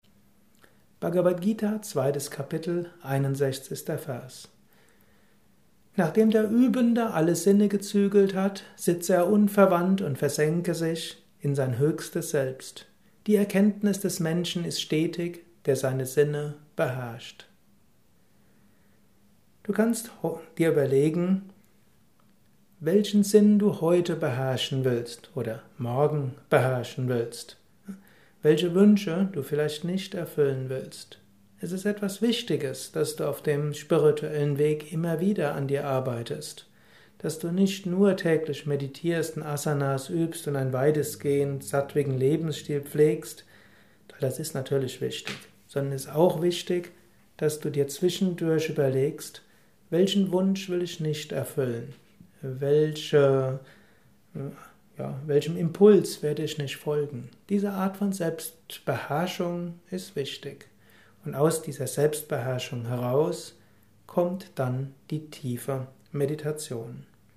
Aufnahme speziell für diesen Podcast.